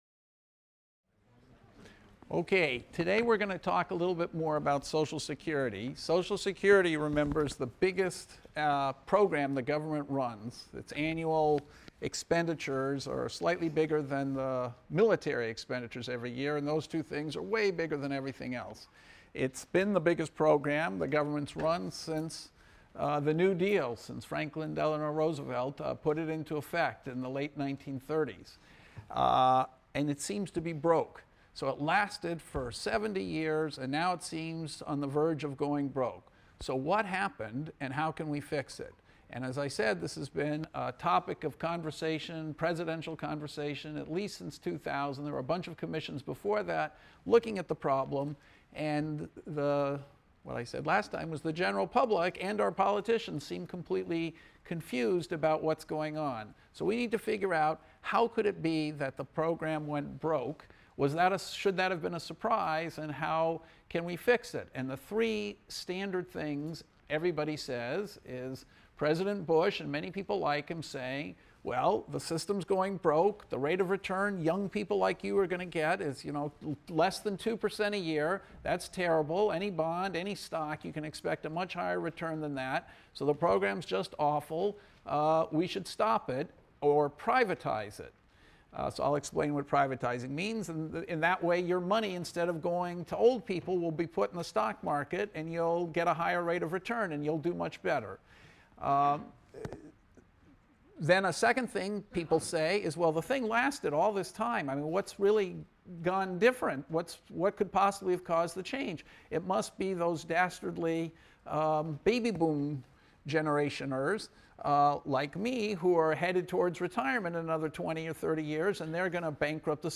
ECON 251 - Lecture 11 - Social Security | Open Yale Courses